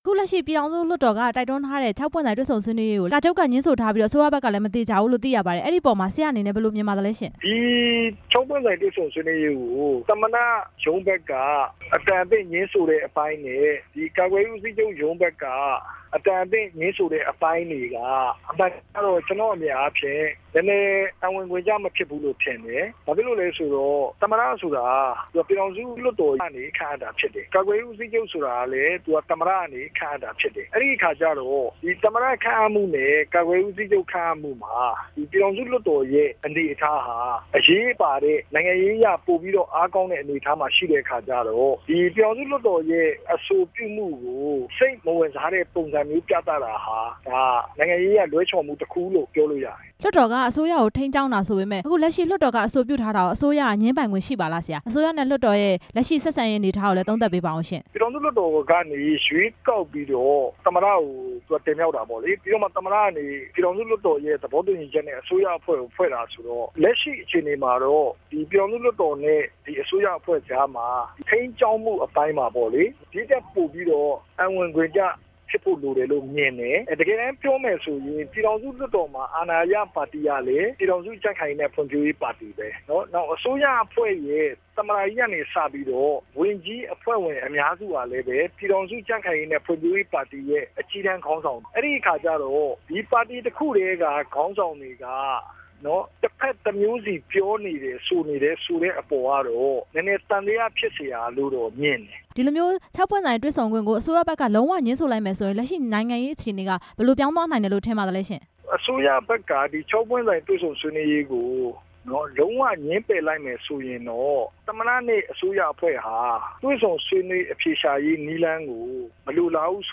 မေးမြန်းချက်
မေးမြန်းချက်အပြည့်အစုံ